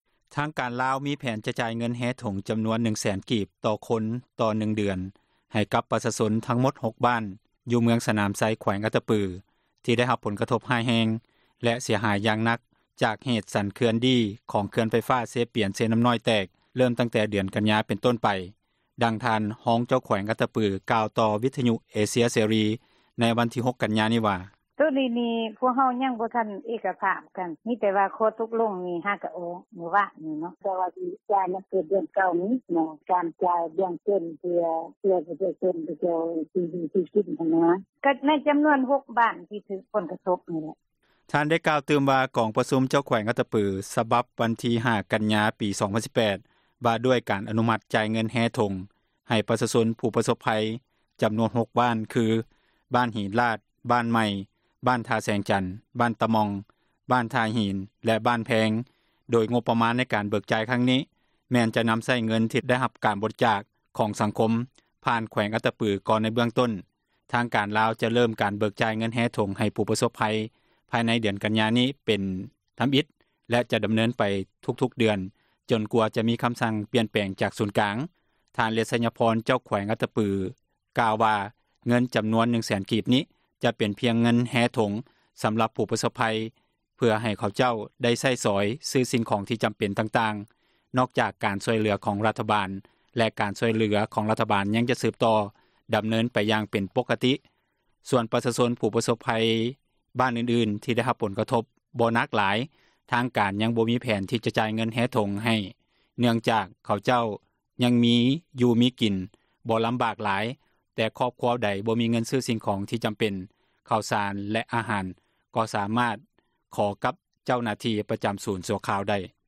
ດັ່ງທ່ານ ຮອງເຈົ້າແຂວງອັດຕະປື ກ່າວຕໍ່ວິທຍຸ ເອເຊັຍເສຣີ ໃນວັນທີ 06 ກັນຍາ ວ່າ: